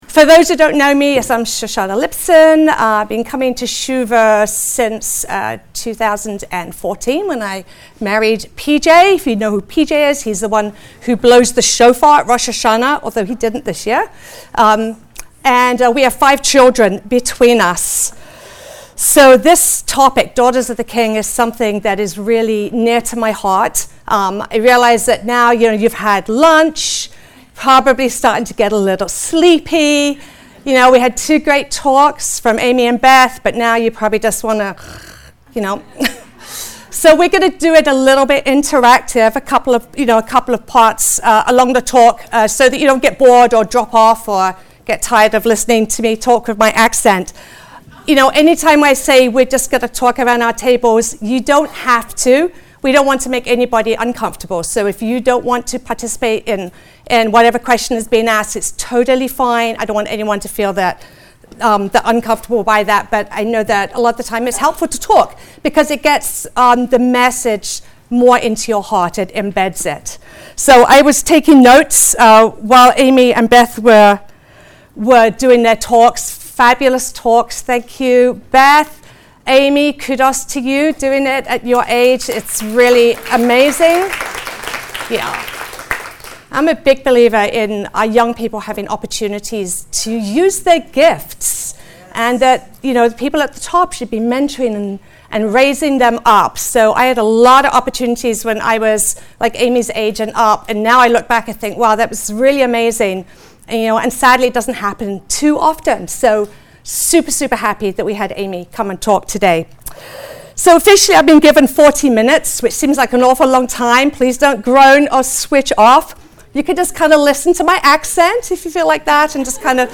Women's Retreat